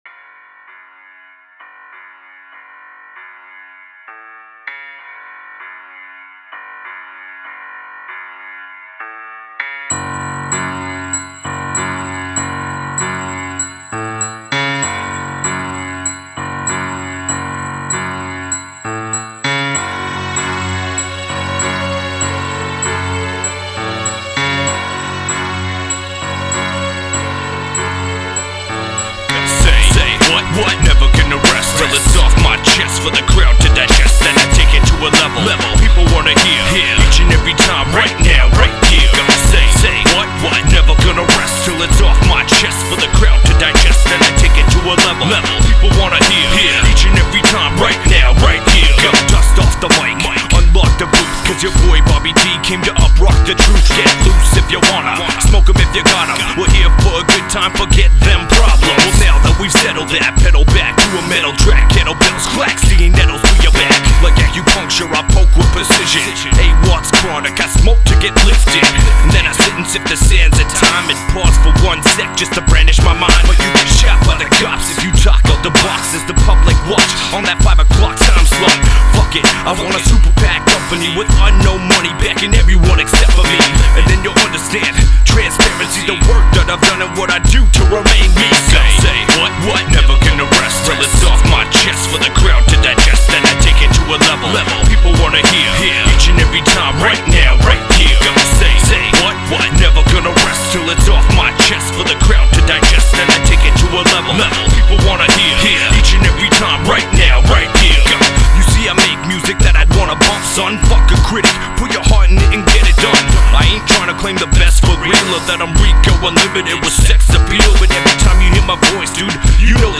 energetic tracks with dark undertones
real hip hop from the heart
Recorded at Ground Zero Studios